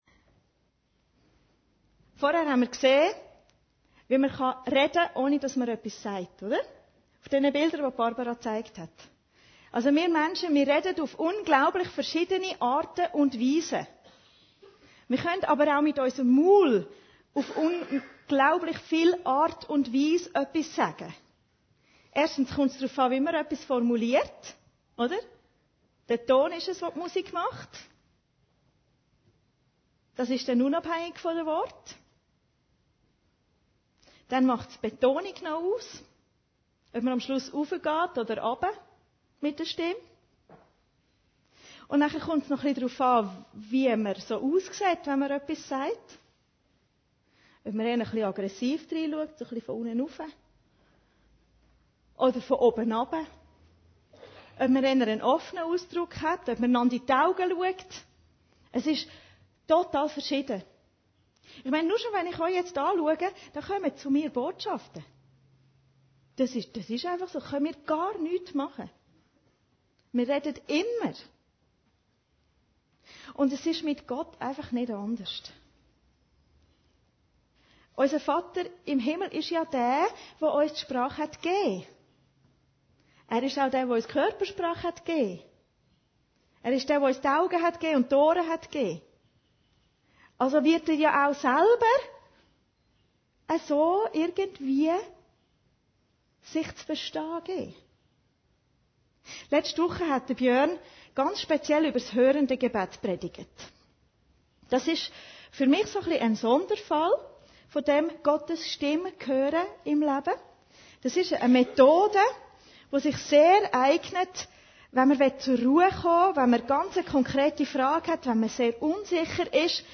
Predigten Heilsarmee Aargau Süd – Gottes Stimme hören, Teil 2